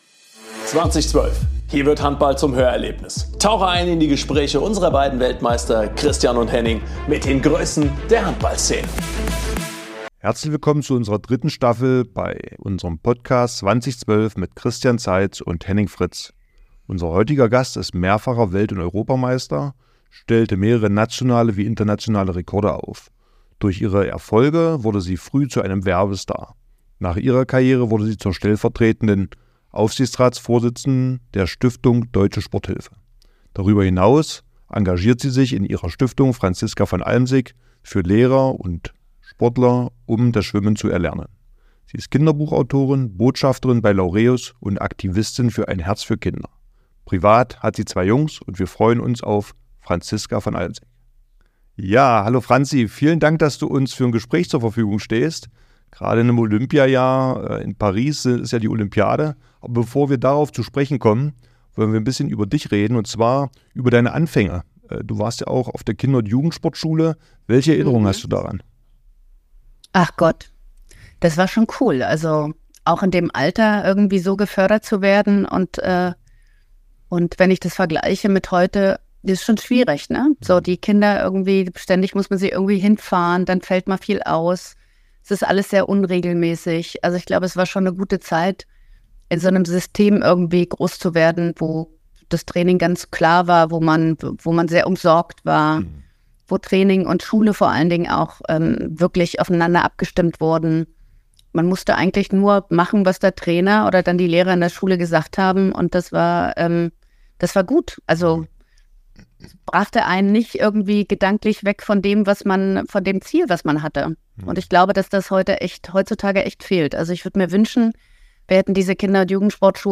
In der ersten Folge sprechen unsere Gastgeber mit Franziska van Almsick über ihre Olympia-Erlebnisse, den Schwimmsport, wie hart Einzelsport ist und vieles mehr.